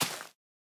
Minecraft Version Minecraft Version latest Latest Release | Latest Snapshot latest / assets / minecraft / sounds / block / big_dripleaf / tilt_down5.ogg Compare With Compare With Latest Release | Latest Snapshot
tilt_down5.ogg